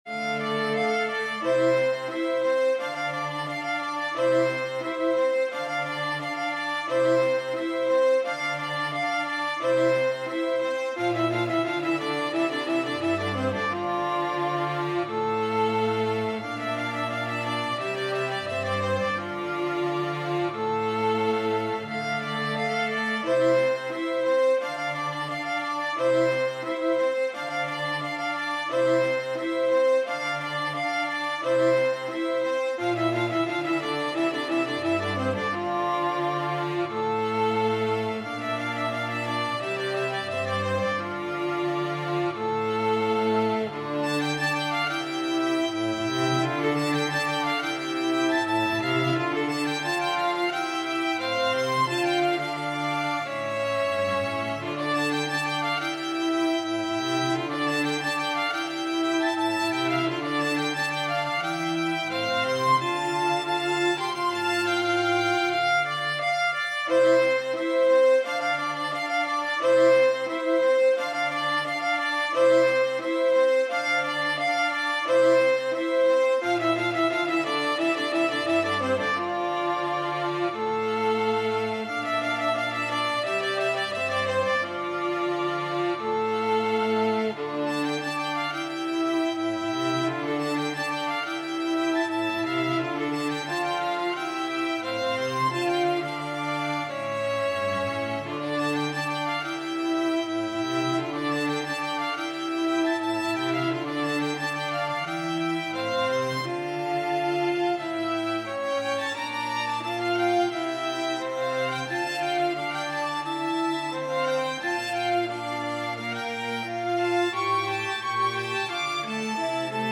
String Trio Buy Now!